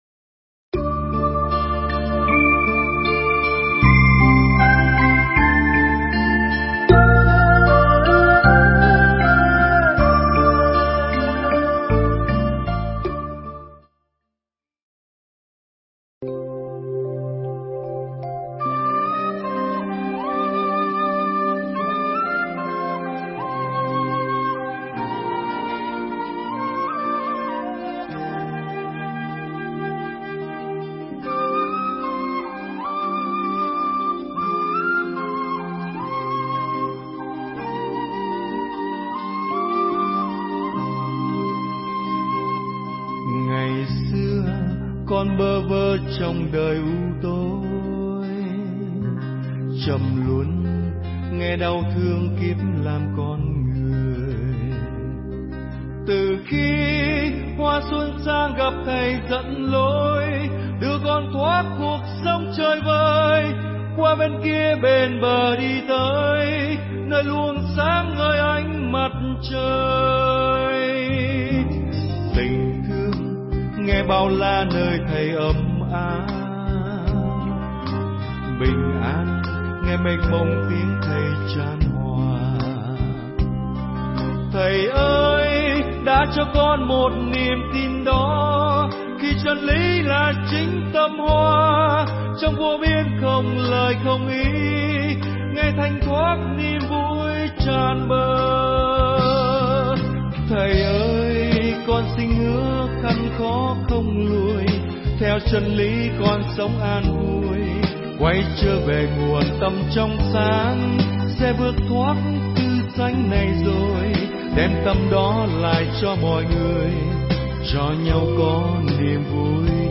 Buổi Nói Chuyện Tại Thính Pháp Đường TV Trúc Lâm Đà Lạt 4
buoi-noi-chuyen-tai-thinh-phap-duong-TV-truc-lam-da-lat-4-thich-thanh-tu-anhdepfree.com_.mp3